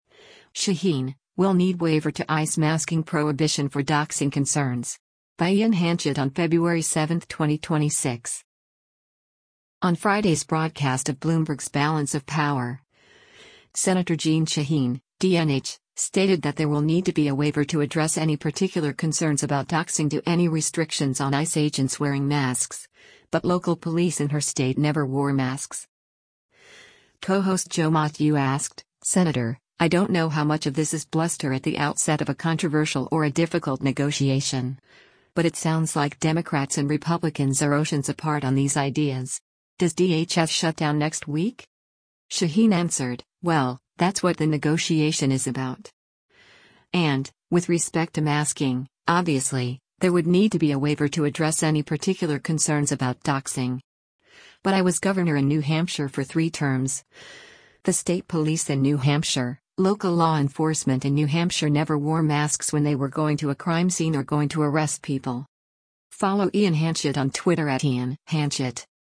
On Friday’s broadcast of Bloomberg’s “Balance of Power,” Sen. Jeanne Shaheen (D-NH) stated that there will “need to be a waiver to address any particular concerns about doxxing” to any restrictions on ICE agents wearing masks, but local police in her state never wore masks.